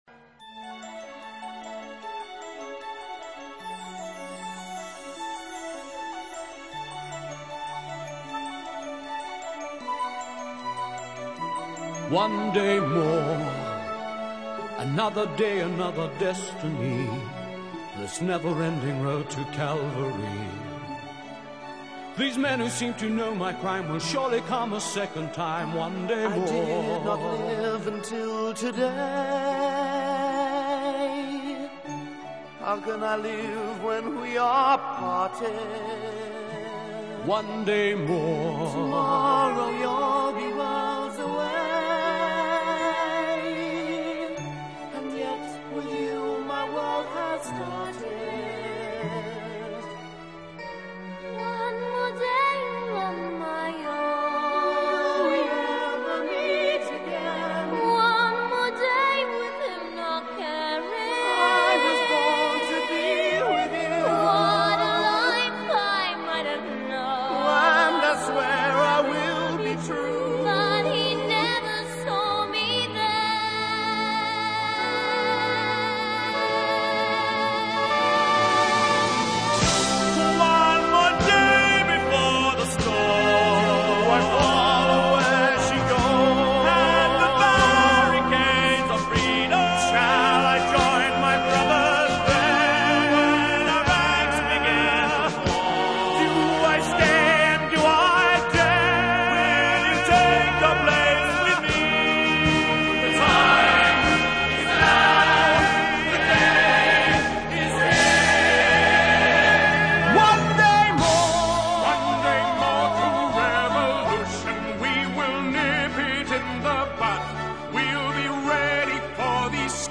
倫敦版的像是在唸歌，所謂唸歌的意思是字正腔圓地一字一字唱…在這裡我就比較喜歡